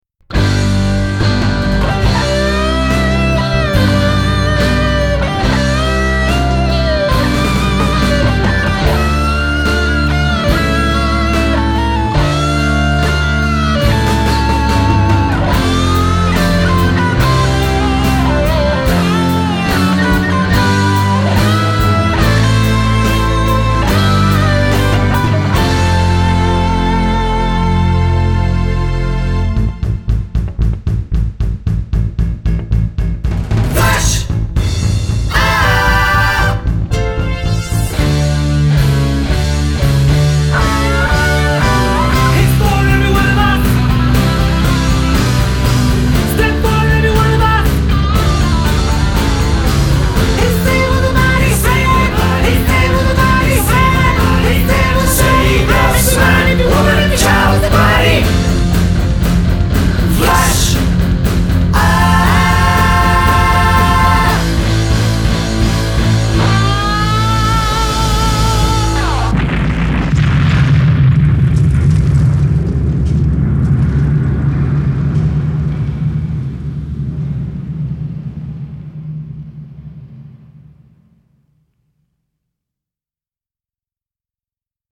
Treble Booster Super used for all guitar sounds
using Vox AC30 and Deacy Amp